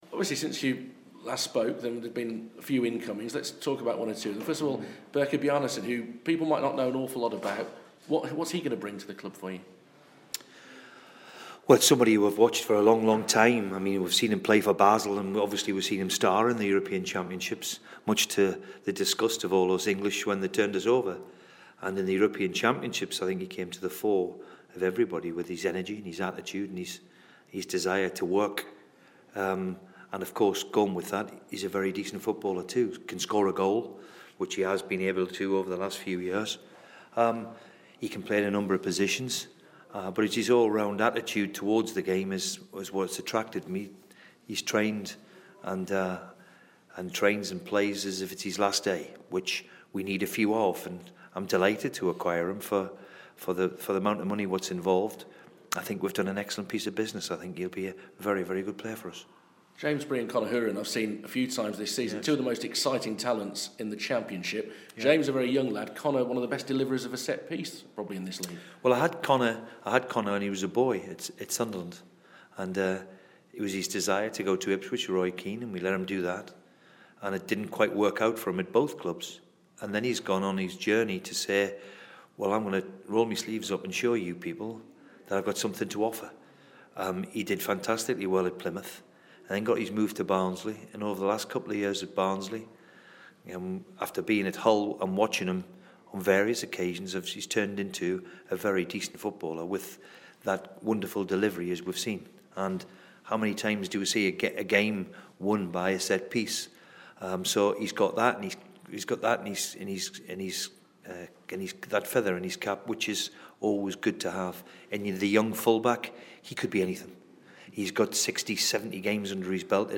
Villa boss Steve Bruce talks to BBC WM about the signings he's made this month and those he still hopes to make before Tuesdays deadline.